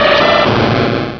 pokeemerald / sound / direct_sound_samples / cries / feraligatr.aif